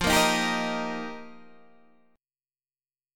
F7sus2sus4 chord